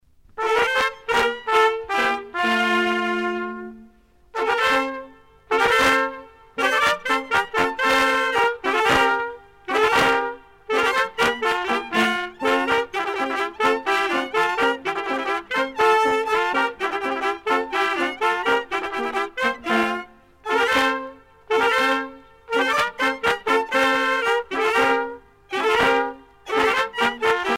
danse : polka lapin
groupe folklorique
Pièce musicale éditée